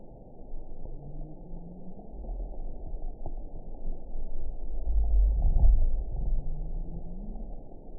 event 910522 date 01/22/22 time 00:48:25 GMT (3 years, 3 months ago) score 6.34 location TSS-AB06 detected by nrw target species NRW annotations +NRW Spectrogram: Frequency (kHz) vs. Time (s) audio not available .wav